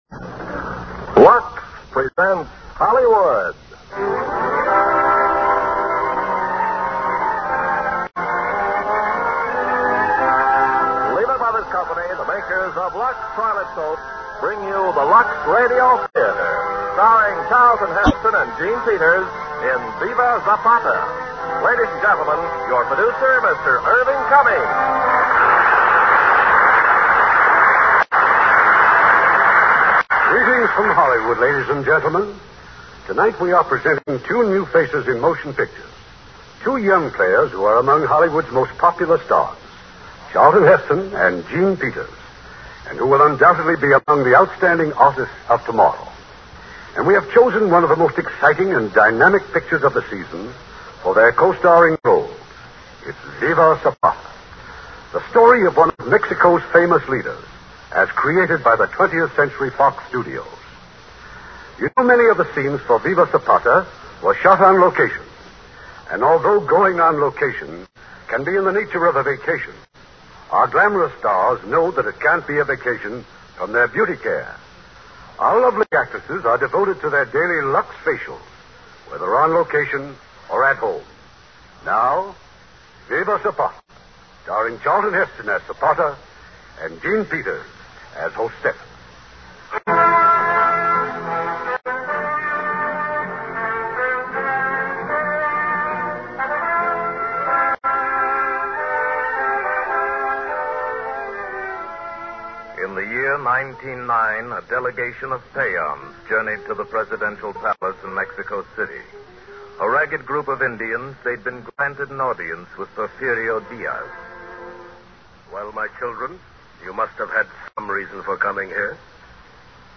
starring Charleton Heston, Jean Peters